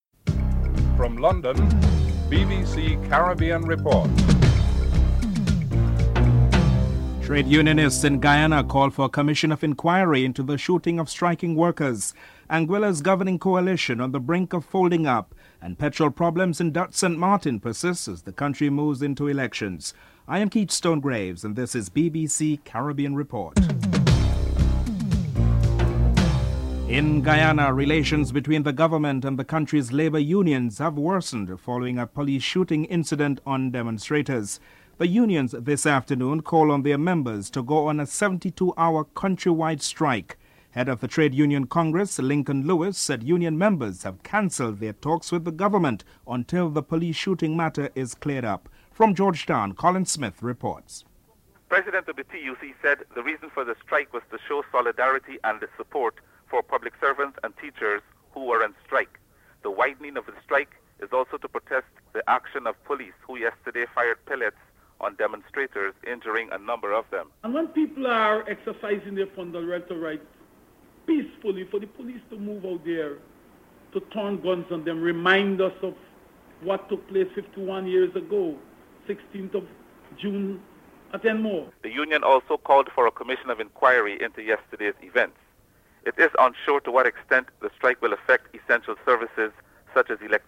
The Anguillan government is heading into conflict with two members from the coalition Anguilla Democratic Party threatening to resign. Prime Minister Hubert Hughes and Finance Minister Victor Banks comment on the political impasse (04:11 – 07: 51)